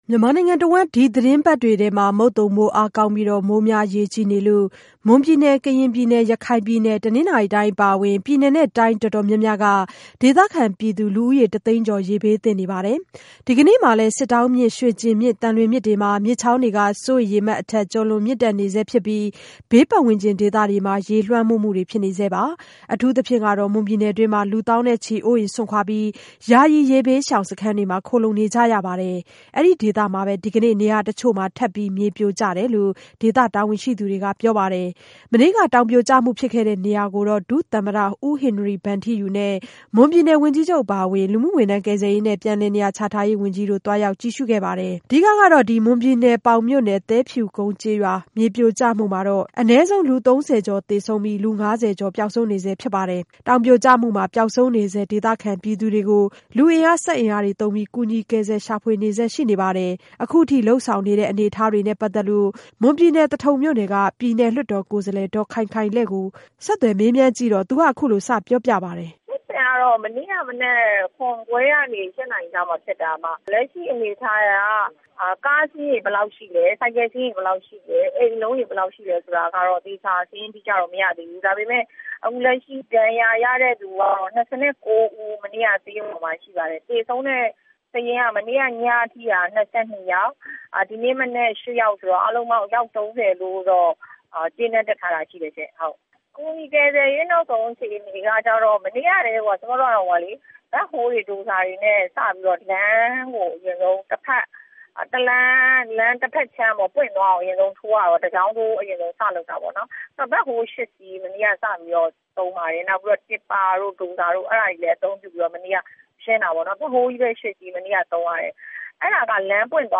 မွန်ပြည်နယ် သဘာဝဘေးအခြေအနေ ပြည်နယ်အမတ် မေးမြန်းချက်